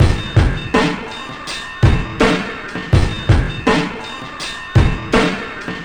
Rusty Drum Break 82bpm.wav